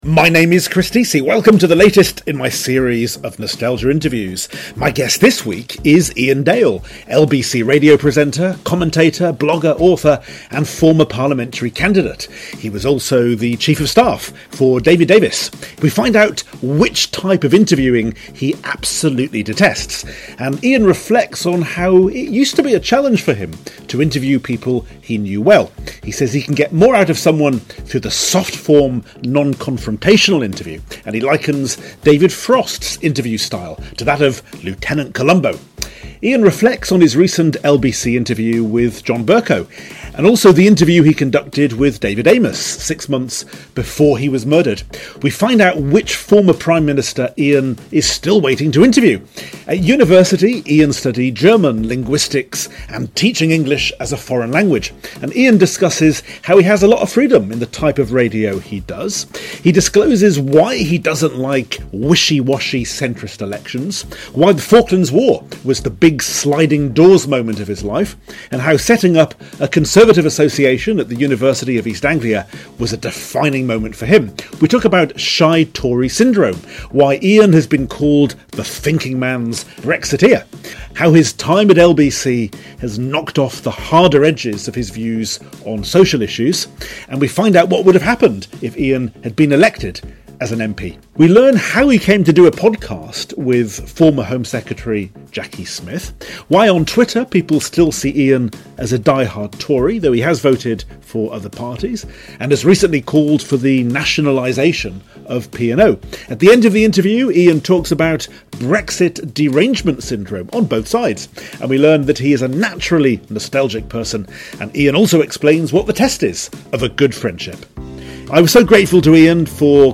My guest this week is Iain Dale, radio presenter, commentator, blogger, publisher, author and former Parliamentary candidate. Iain talks about why he turns down many TV invitations these days, why he hates the ‘gotcha’ style of interviewing and why the long form interview is b...